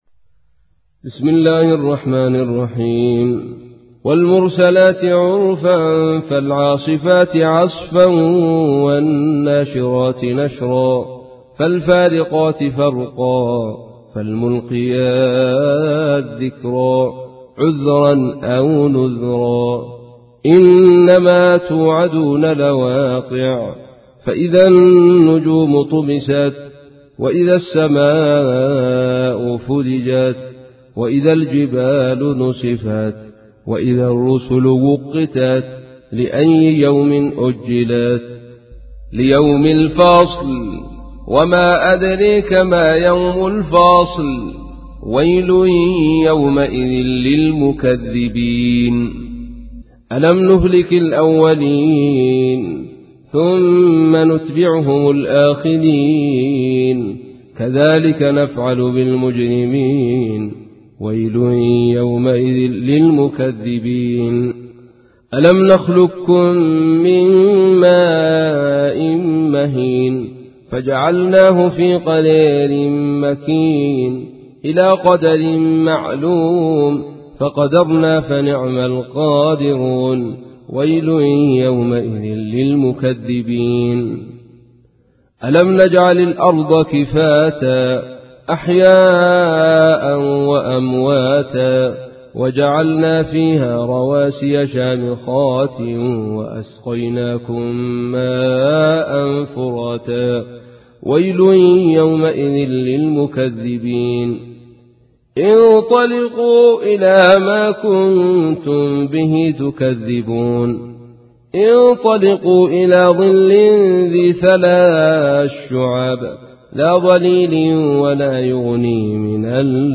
تحميل : 77. سورة المرسلات / القارئ عبد الرشيد صوفي / القرآن الكريم / موقع يا حسين